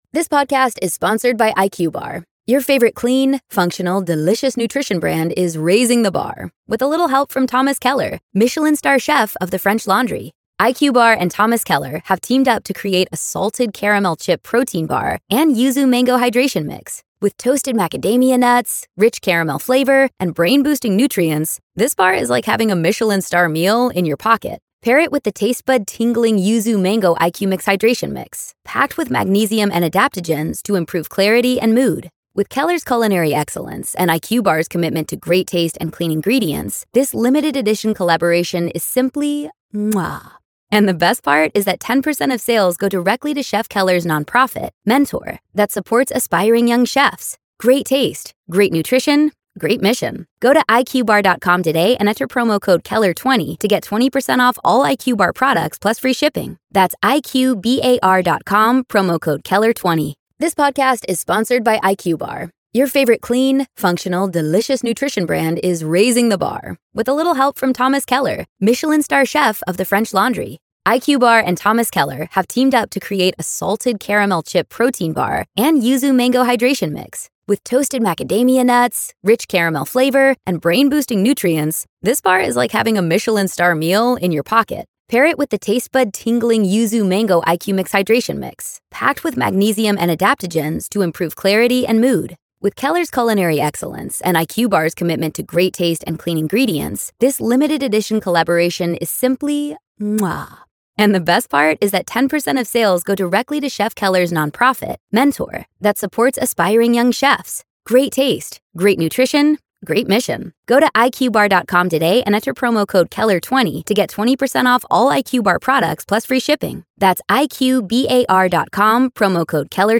The conversation reflects on how laws, like the Red Flag Law, offer potential solutions but come with challenges in implementation and potential for abuse. They conclude that while no single solution is apparent, collaborative efforts between parents, schools, and authorities could help prevent future tragedies.